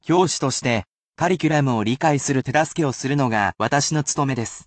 And, I will never become bored of saying said word, as I am a computer robot, and repetitive tasks are my [ｉｎｓｅｒｔ　ｌｏｃａｌ　Ｅａｒｔｈ　ｗｉｎｔｅｒ　ｈｏｌｉｄａｙ].